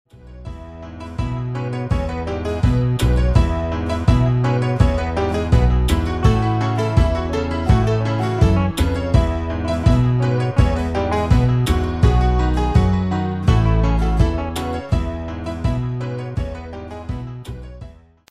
Demo/Koop midifile
Genre: Evergreens & oldies
Toonsoort: E
- Vocal harmony tracks